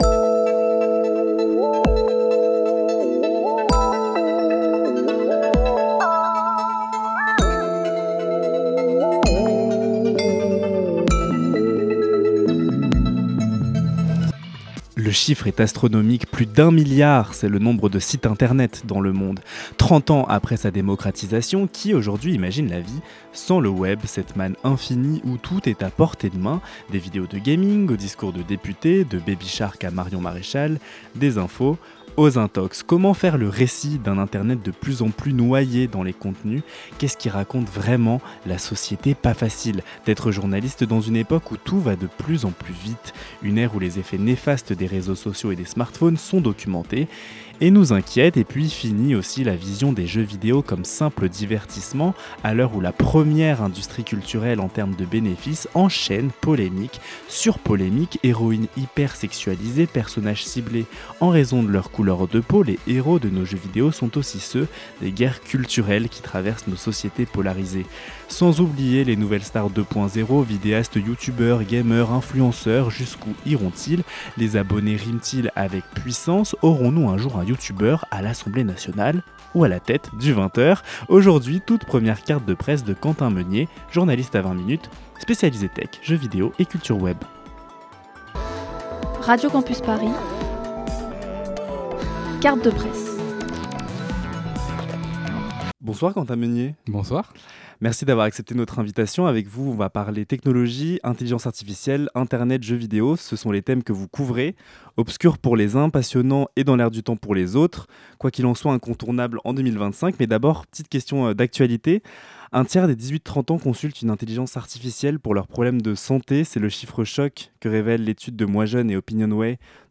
Partager Type Entretien Société mardi 11 novembre 2025 Lire Pause Télécharger Voilà plus de trente ans qu'Internet a fait irruption dans nos vies.